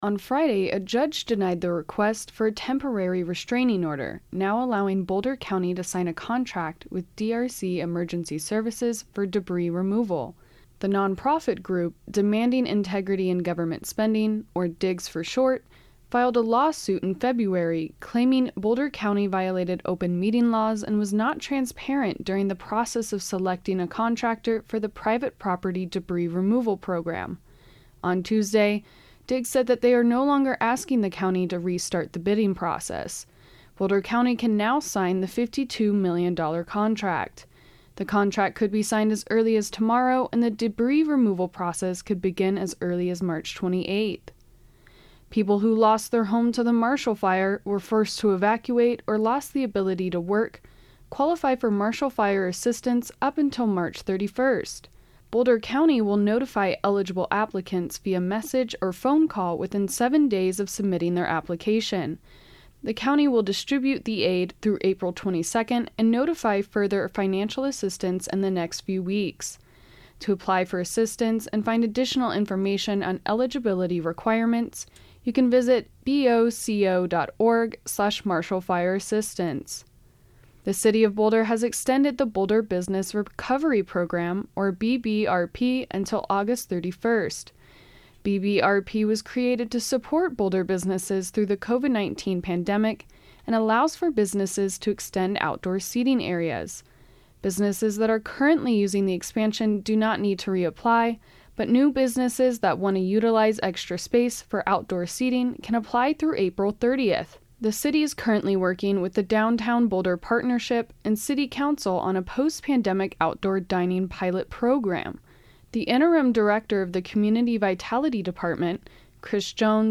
Headlines March 21, 2022